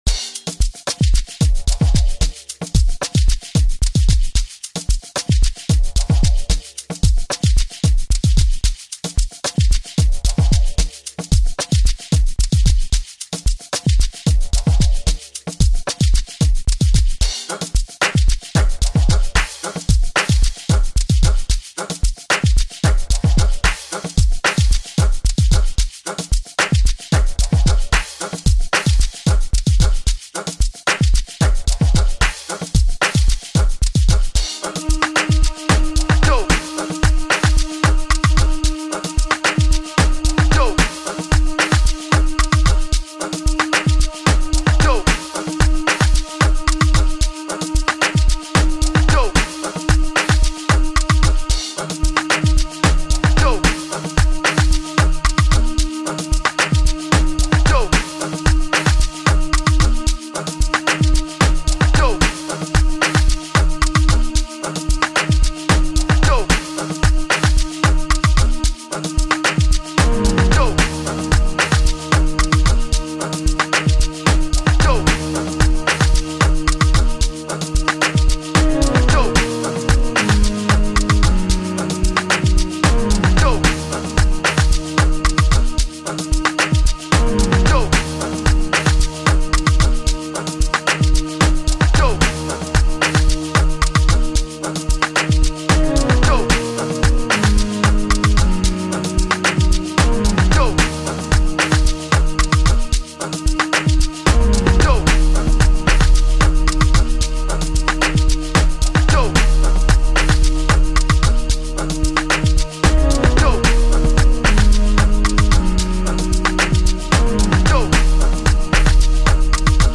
We bless you with another one from Amapiano prince